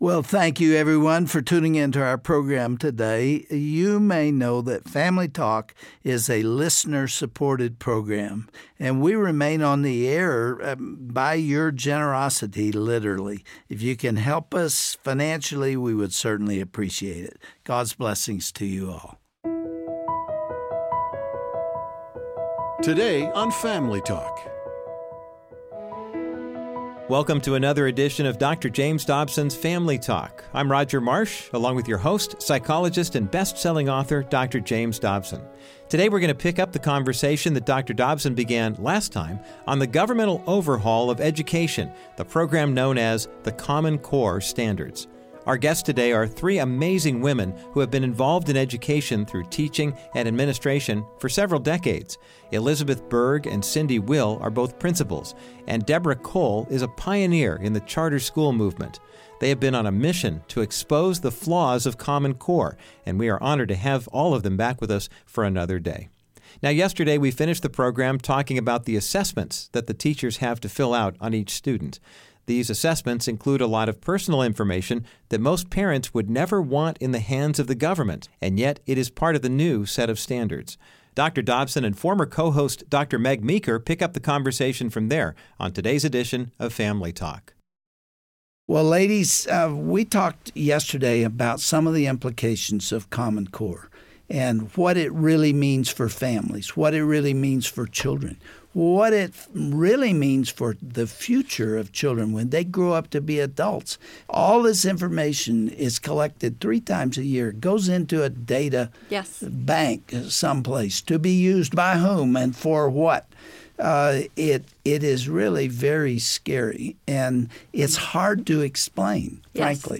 Isnt the government just trying to make sure our kids get a good education? On the next edition of Family Talk, Dr. James Dobson interviews a panel of administrators about the problem with common core.